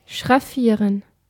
Ääntäminen
IPA: /ʃʁaˈfiːʁən/ IPA: [ʃʁaˈfiːɐ̯n]